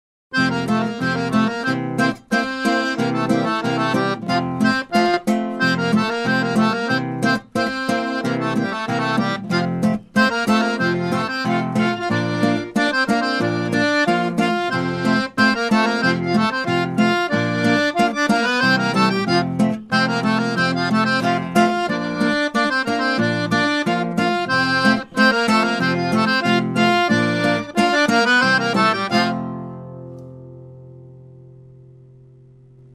Música Contradança